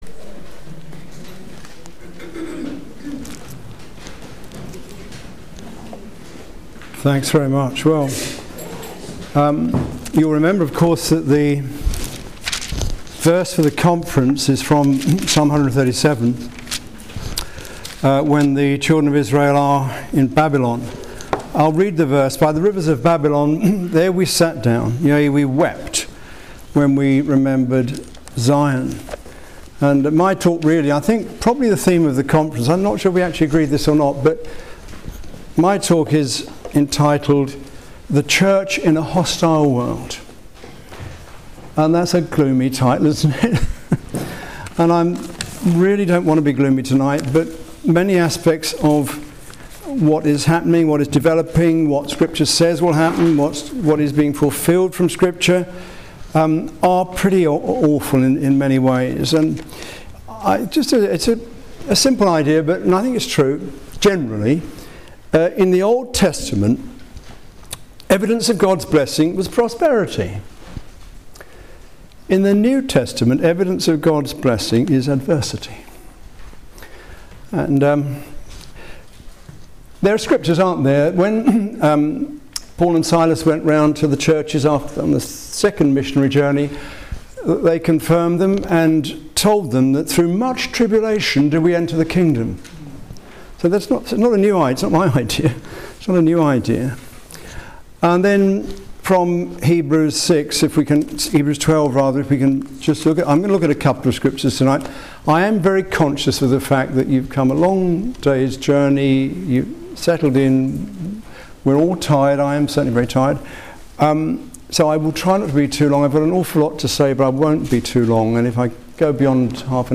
Psalm 137:1 Service Type: Christian Alliance Ministries Conference « “Examine Yourselves